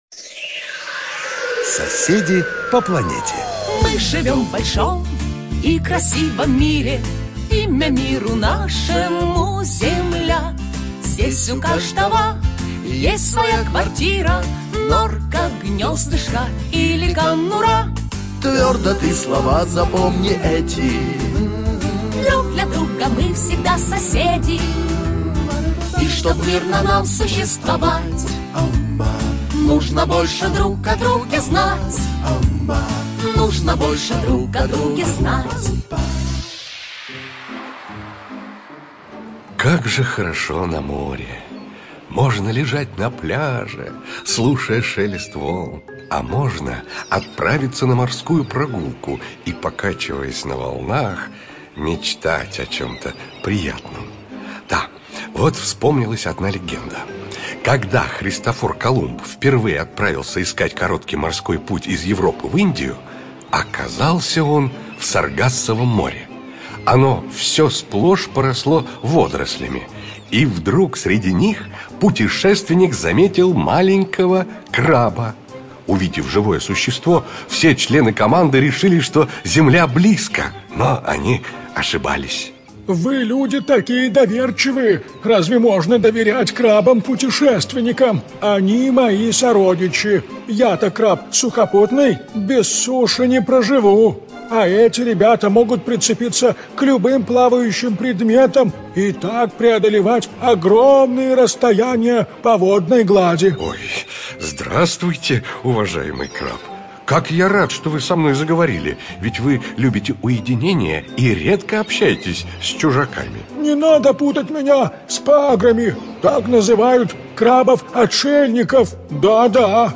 Студия звукозаписиДетское радио
_Аудиоэнциклопедия для детей - Соседи по планете. Выпуски  61 - 80 (Детское радио)(preview).mp3